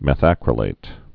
(mĕth-ăkrə-lāt)